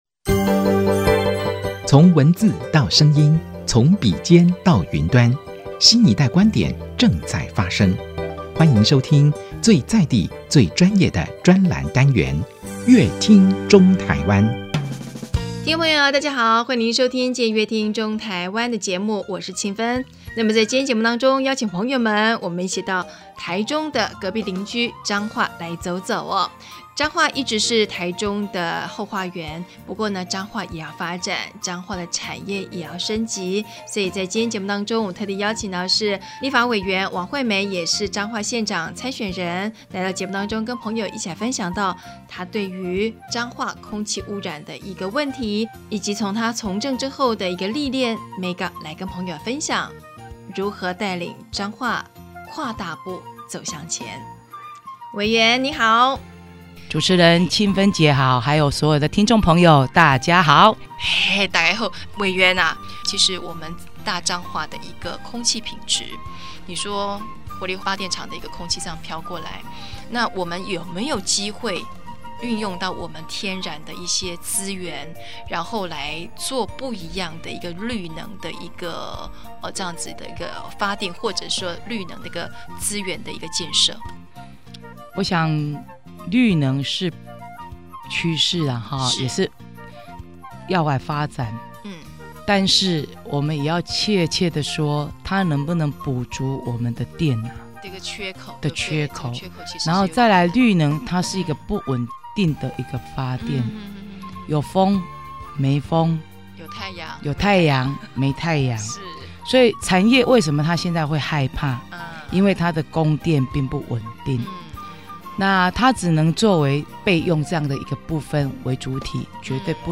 本集來賓：王惠美立法委員 本集主題：用同理心帶領彰化跨大步走向前 本集內容： 天還微微亮，一般人還賴在床上，但有人已經起床開始一天的行程，跑攤、再到立法院開會，為民眾切身的議題把關、喉舌，她是立法委員王惠美。